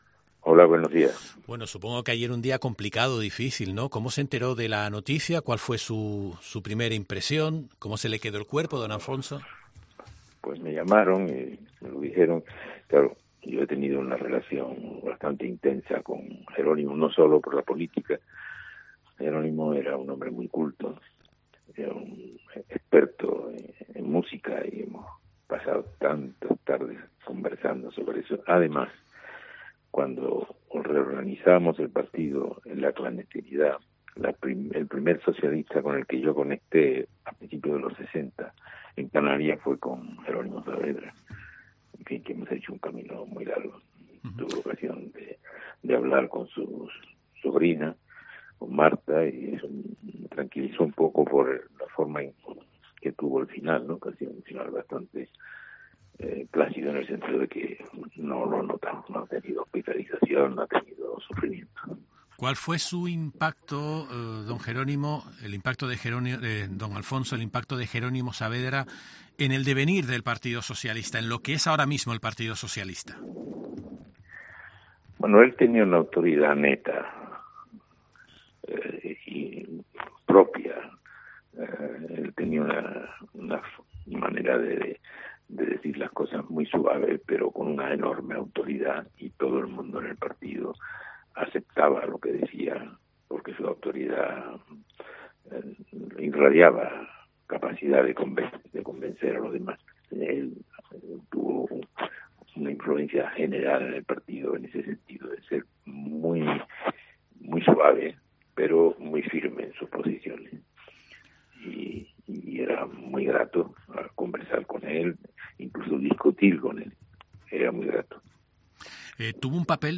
Es el caso de Alfonso Guerra, un histórico del socialismo español que ha pasado por Herrera en COPE Gran Canaria para glosar su vida.